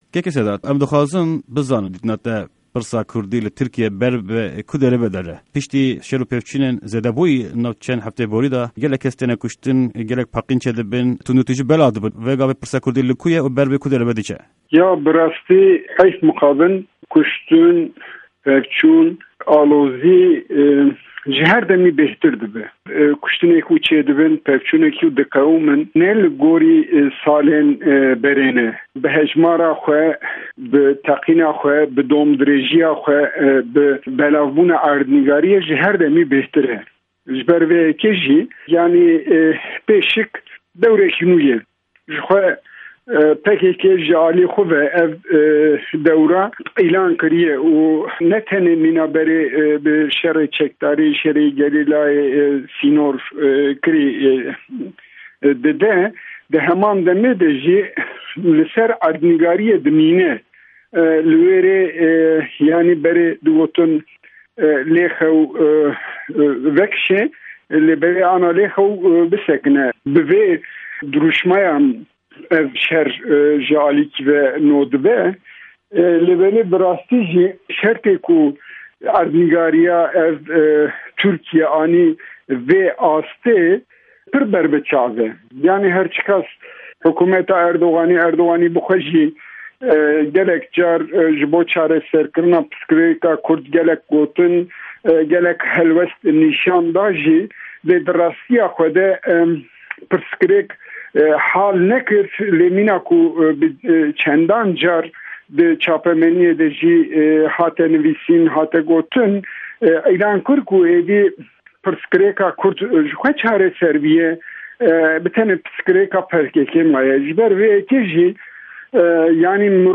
Hevpeyvîn bi Sedat Yurtdaş re